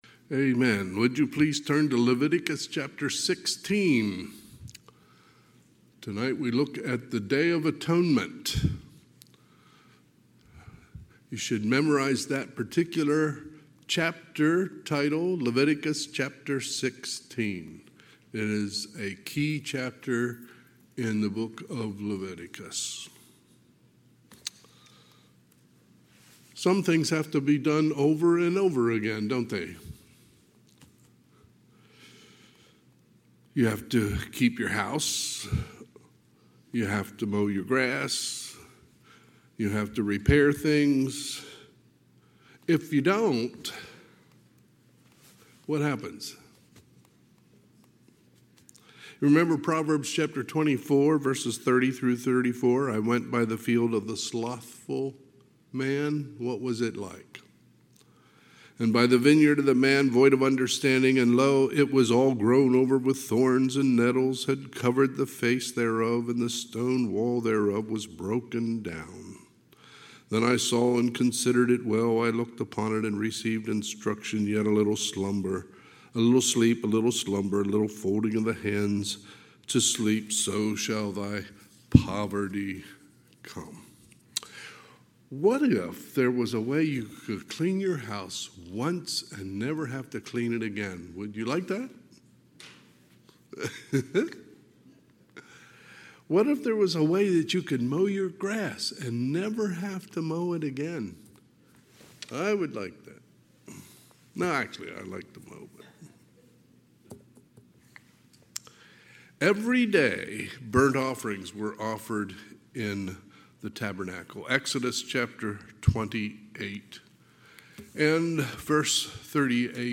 Sunday, April 27, 2025 – Sunday PM